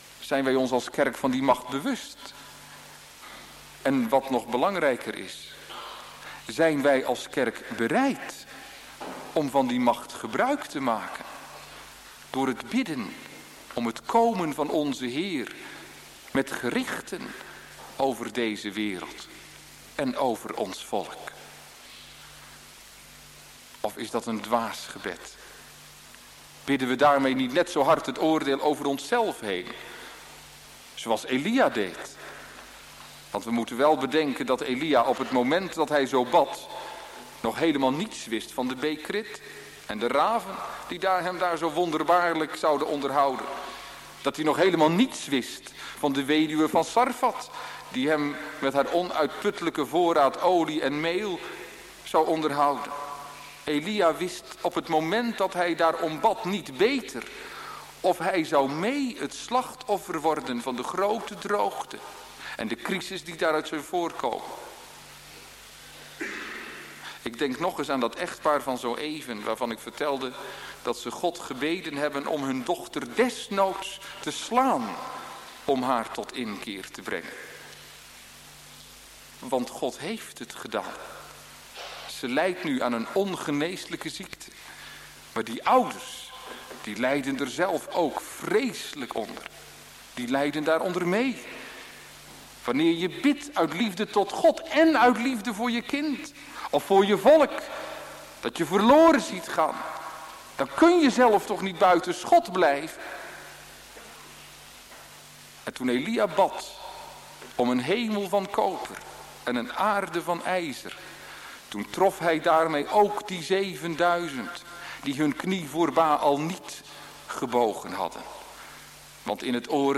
track03c-gebed-elia-preek-deel-c.mp3